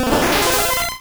Cri de Staross dans Pokémon Rouge et Bleu.